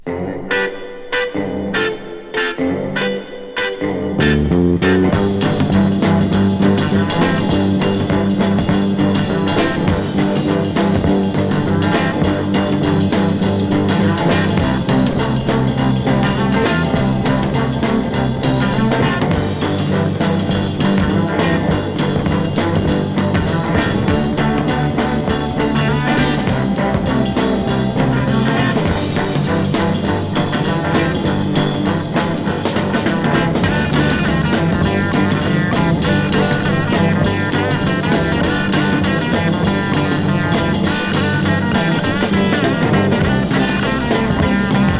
Surf, Rock & Roll　USA　12inchレコード　33rpm　Stereo